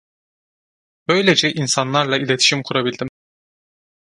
Pronunciado como (IPA)
/i.le.ti.ʃim/